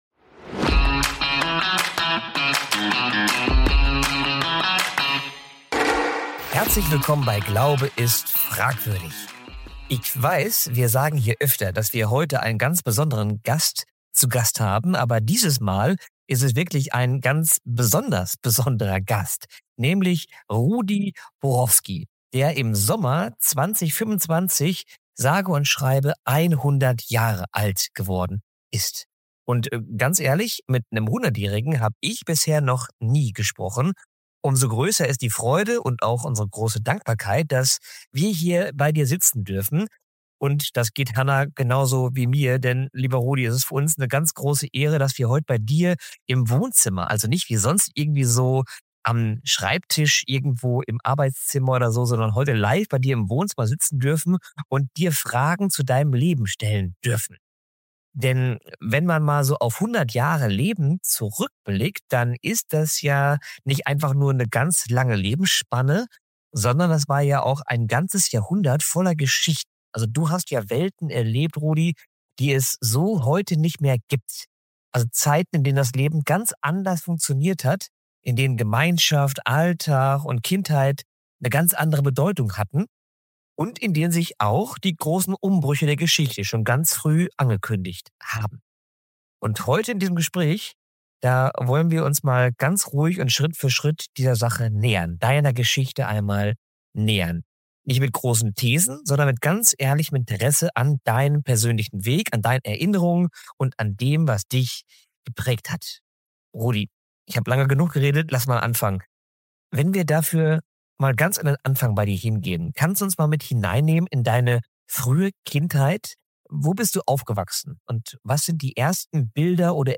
Zeitzeuge